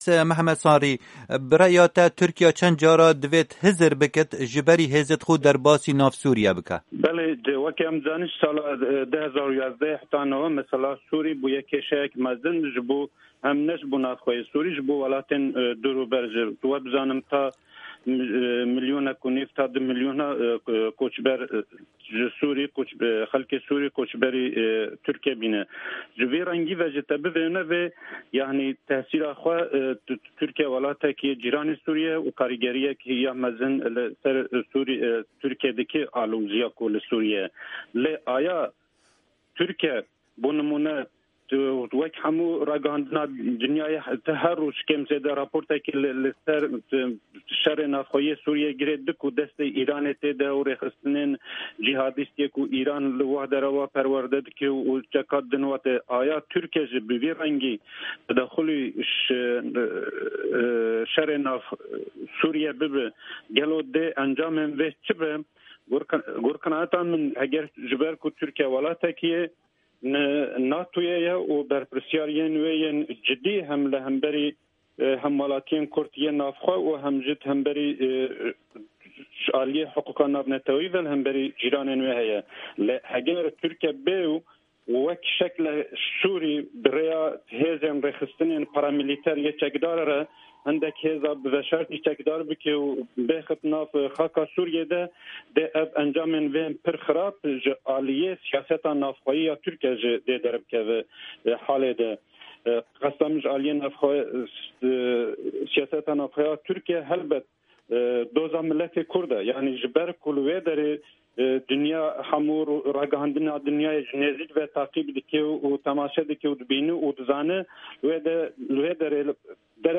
Bêtir şîrove di dirêjahiya vê hevpeyvînê de.